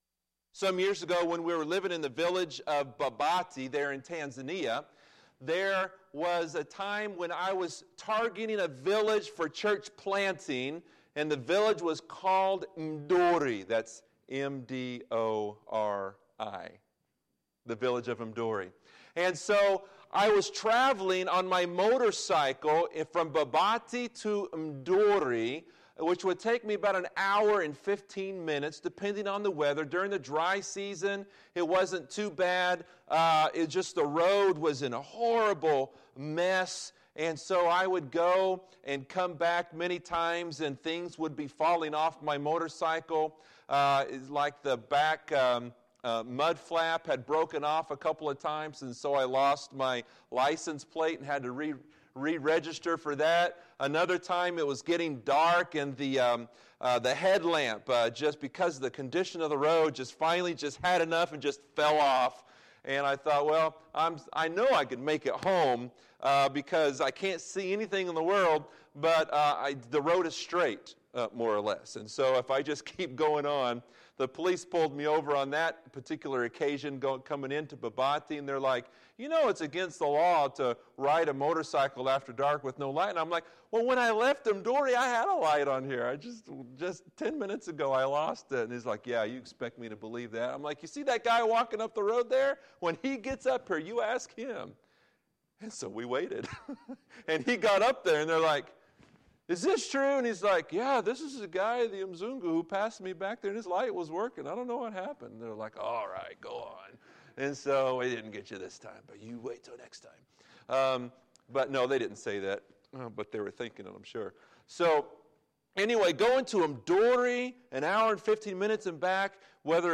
Wednesday, September 27, 2017 – Missions Conference Wednesday PM Service